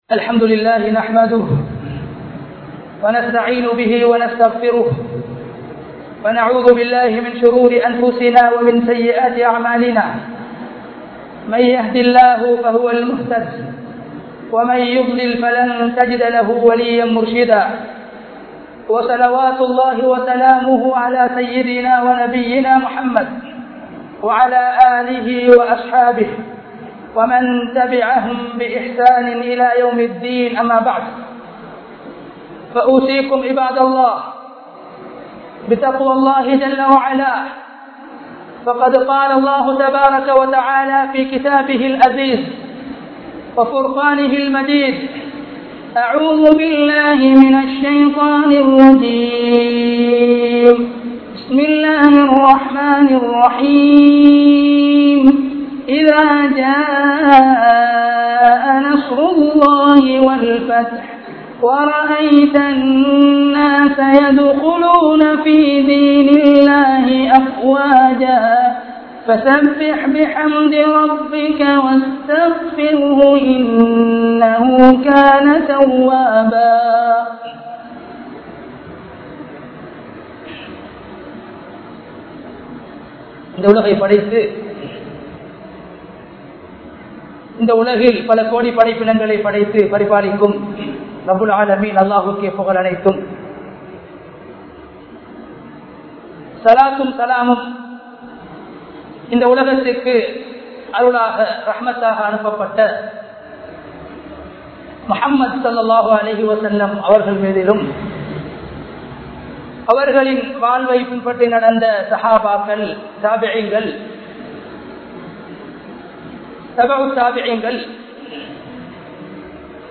Amalhalin Iruthiel Enna Seiya Veandum? (அமல்களின் இறுதியில் என்ன செய்ய வேண்டும்?) | Audio Bayans | All Ceylon Muslim Youth Community | Addalaichenai